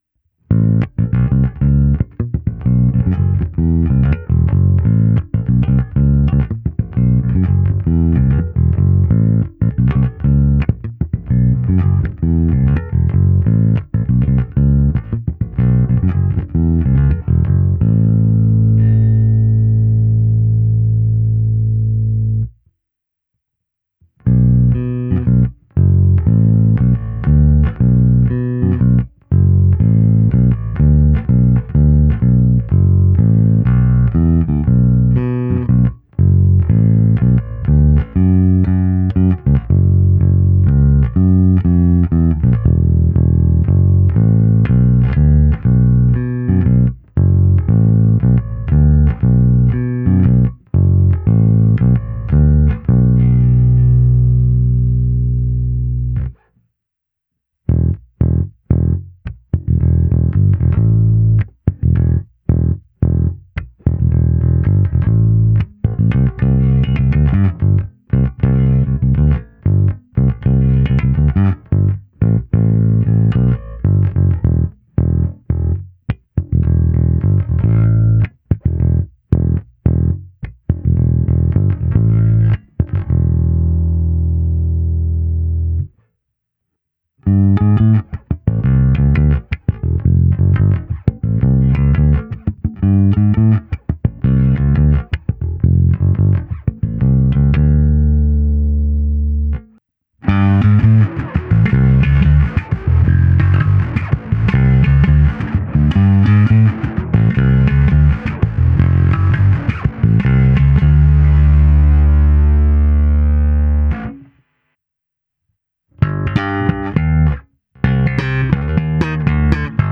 Neskutečně pevný, zvonivý, s těmi správnými středy, co tmelí kapelní zvuk, ale při kterých se basa i prosadí.
Nahrávka se simulací aparátu, kde bylo použito i zkreslení a hra slapem.